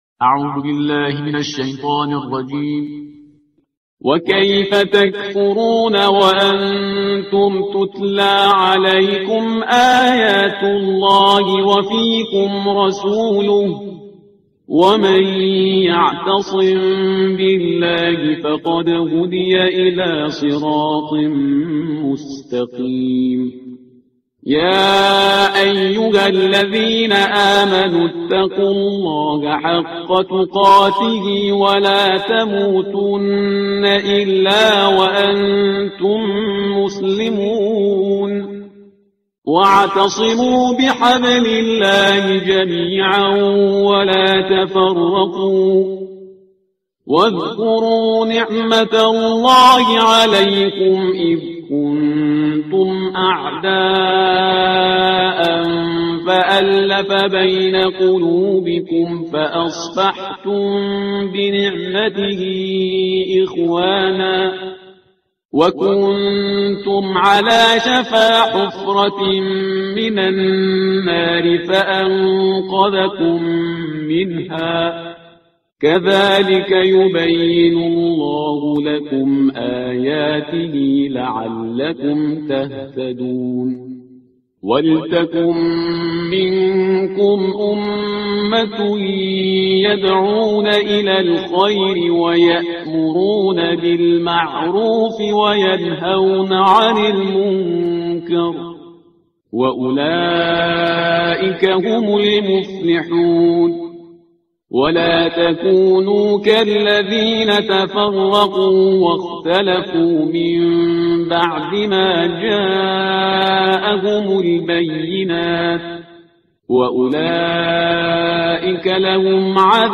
ترتیل صفحه 63 قرآن با صدای شهریار پرهیزگار